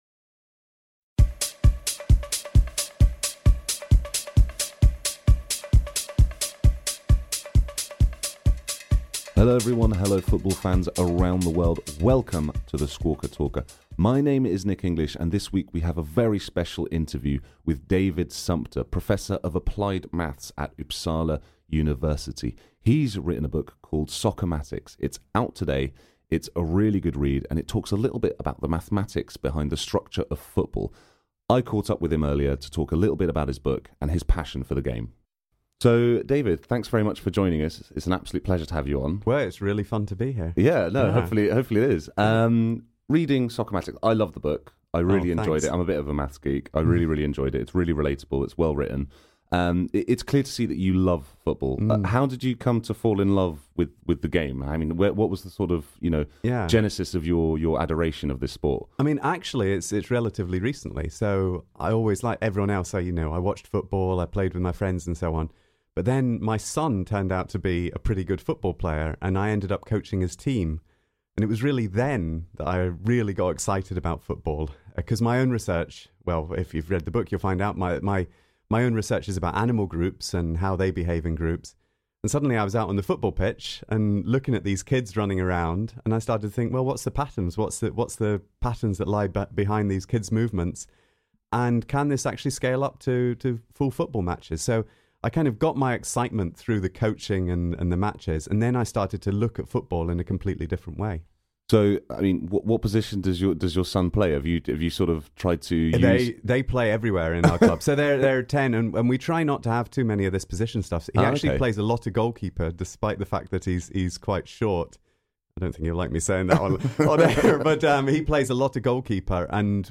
BIG interview